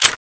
shoot_net1.wav